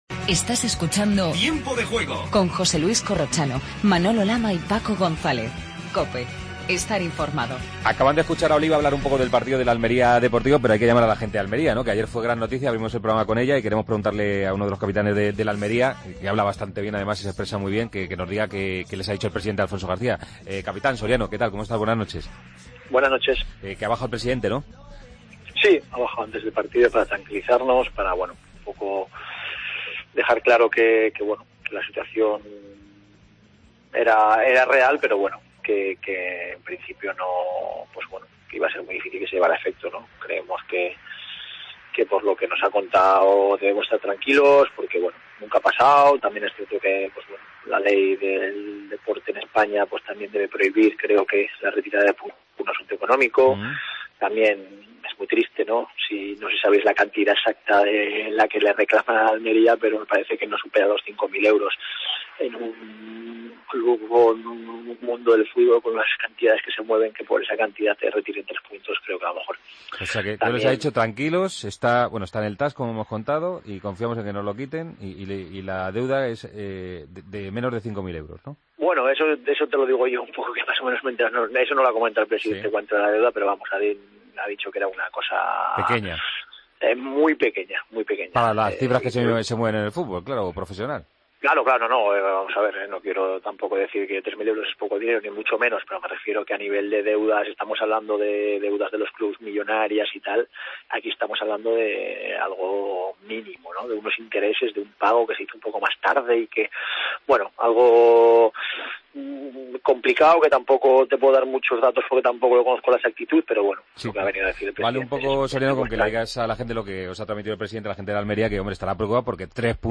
Redacción digital Madrid - Publicado el 01 mar 2015, 01:27 - Actualizado 13 mar 2023, 20:34 1 min lectura Descargar Facebook Twitter Whatsapp Telegram Enviar por email Copiar enlace Hablamos con el jugador del Almería, Soriano, sobre la sanción que ha recibido el conjunto andaluz. Resto de noticias de fútbol. Bloque de Fórmula 1.